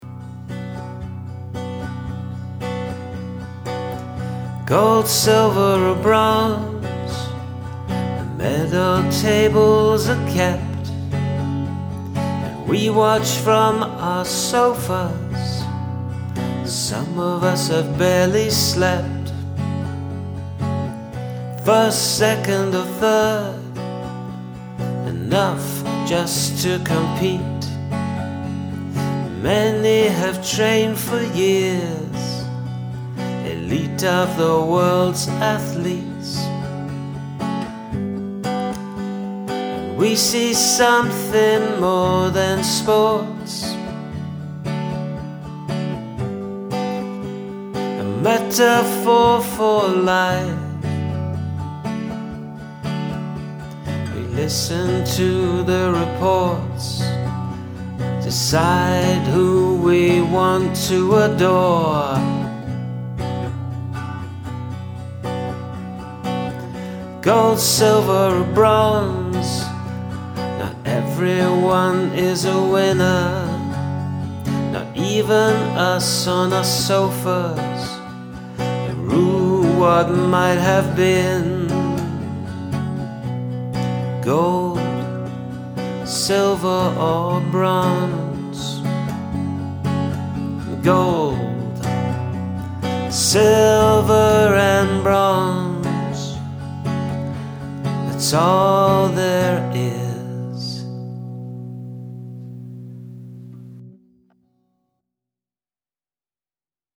It has a melancholy introspective kind of feel to it.
Superb vocals and production; super skirmish!
Lovely vocals and guitar!